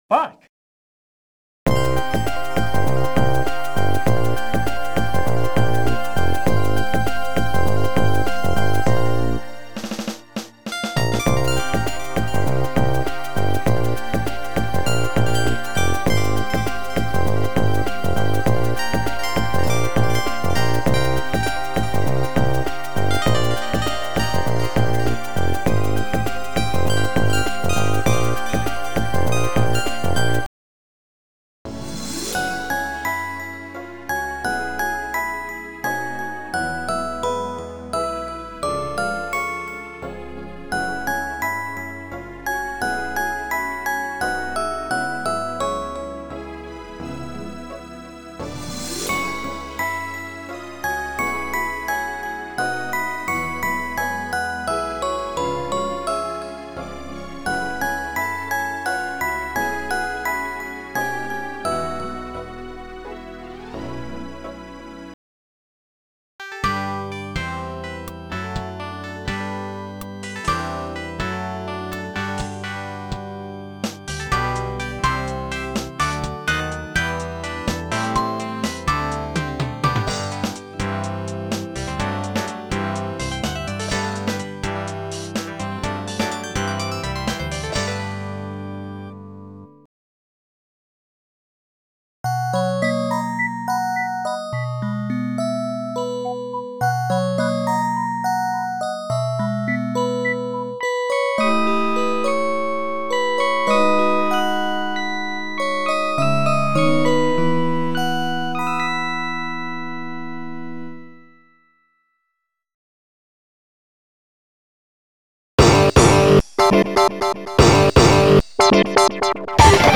mixtape
there are 29 songs total, and it's worth noting most of the songs on the mixtape were made with fm synthesis and that no normalization of any kind was done to any of the songs.